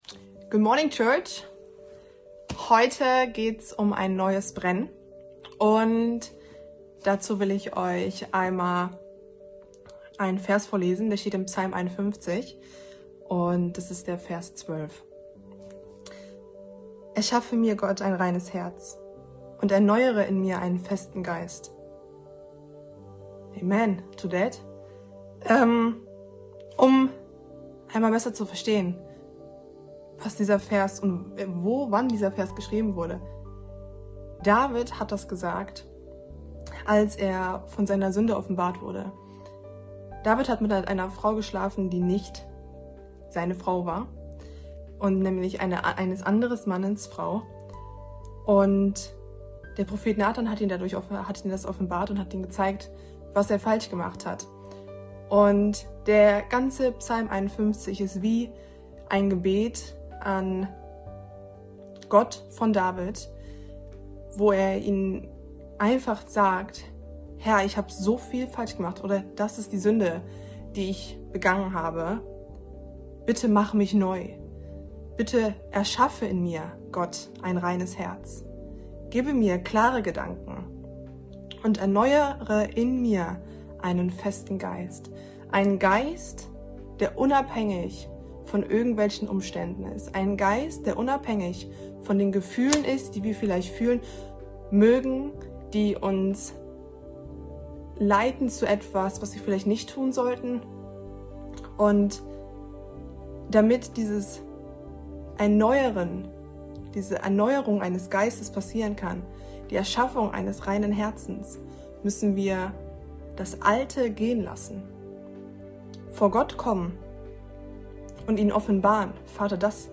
Tag 12 der Andacht zu unseren 21 Tagen Fasten & Gebet